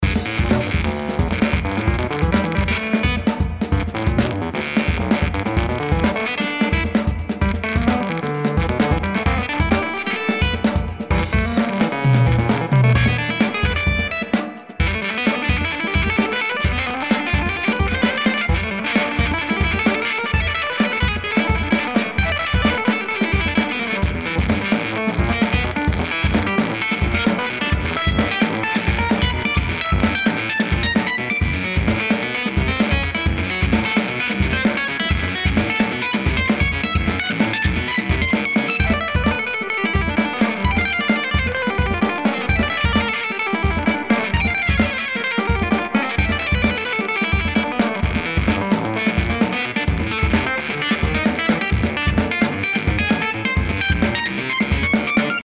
Hier das Pianet – Overdriven: